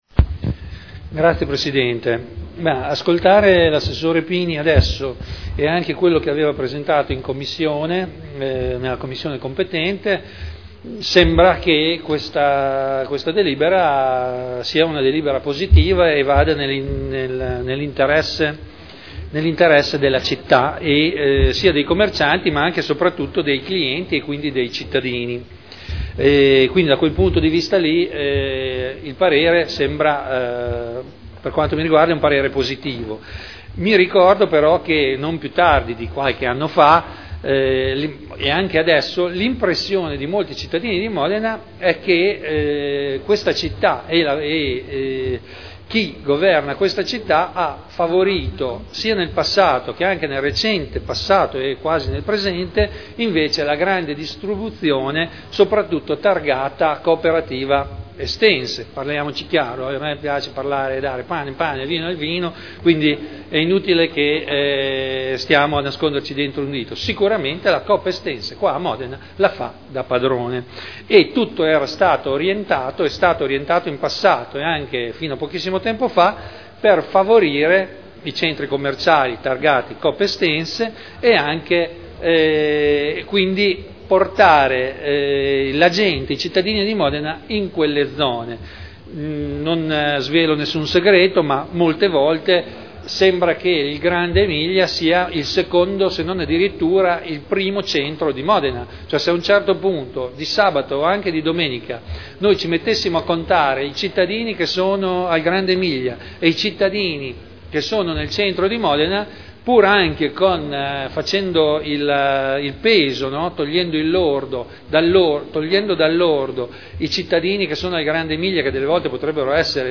Dibattito.